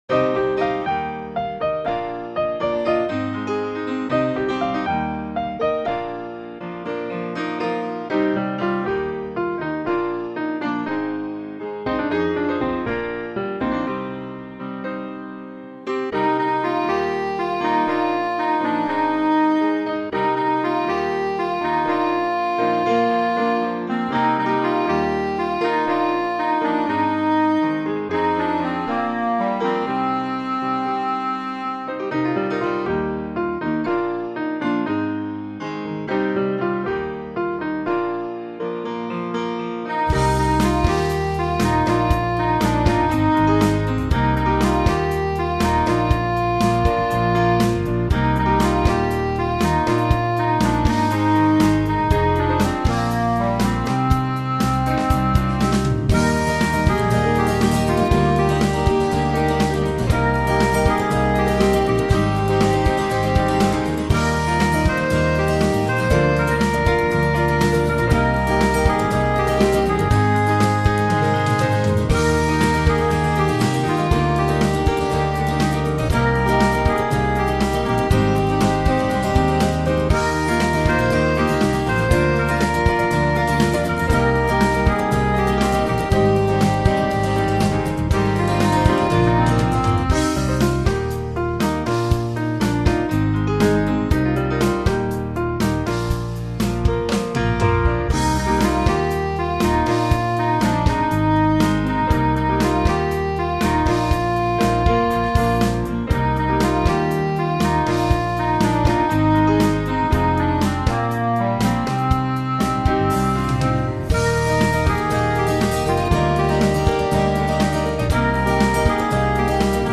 Pentecost song
piano hooks